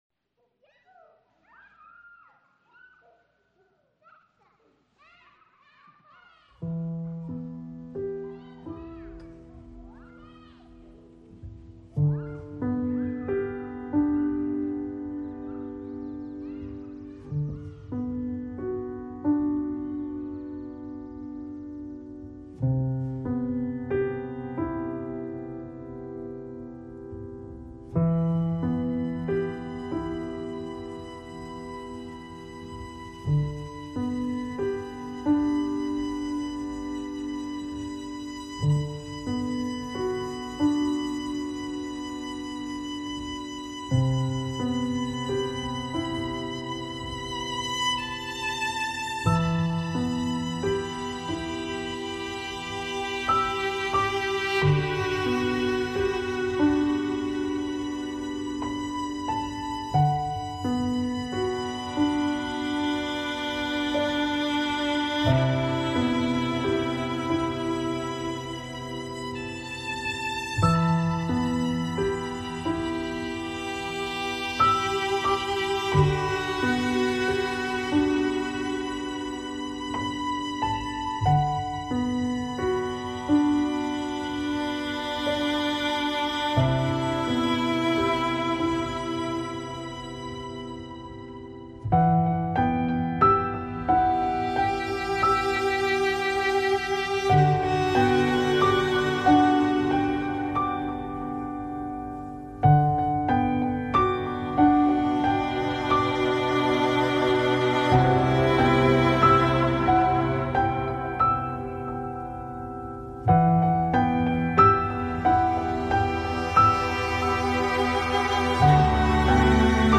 یک موسیقی بی کلام عالی
موسیقی تلفیقی